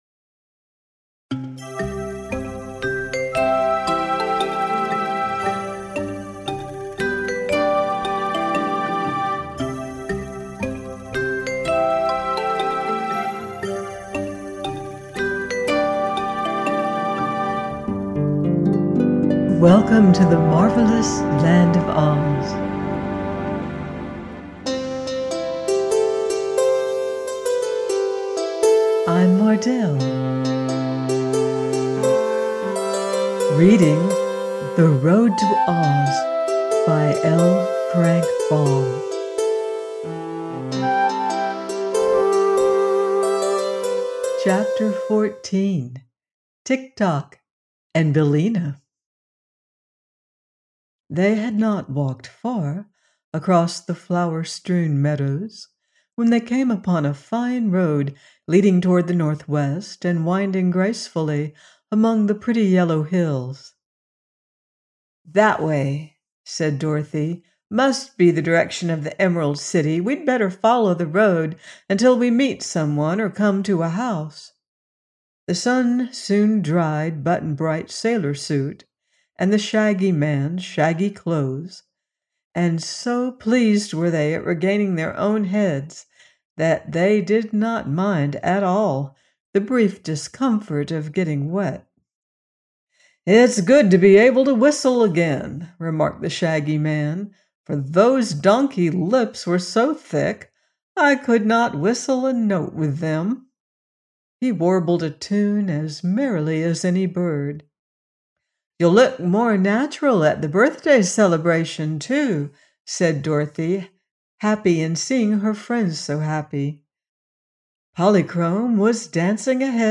The Road To OZ – by L. Frank Baum - audiobook